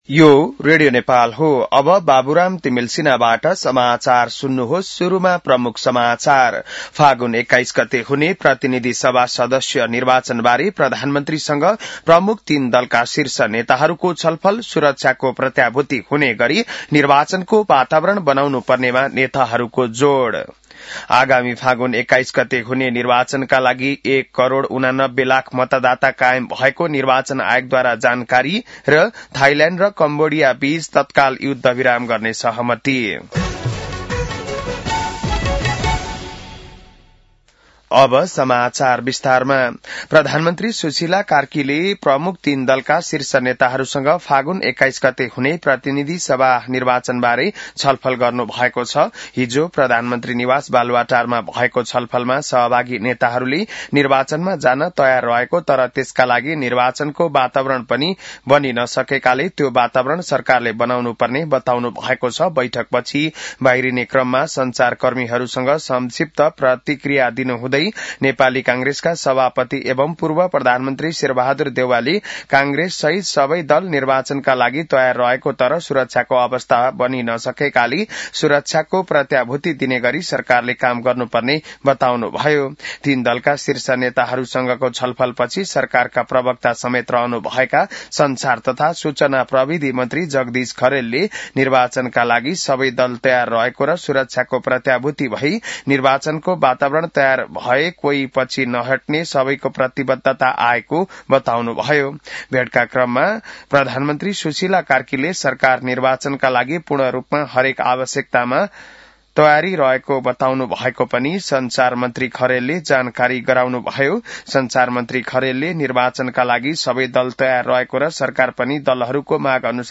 बिहान ९ बजेको नेपाली समाचार : १३ पुष , २०८२